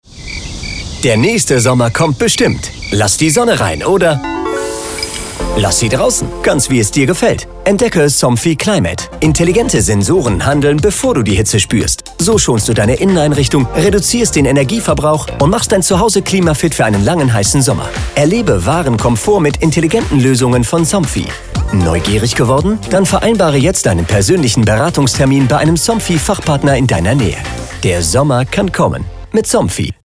Klassischer Radiospot
Somfy_Klassicher-Spot_Beispiel.mp3